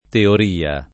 teoria [ teor & a ] s. f.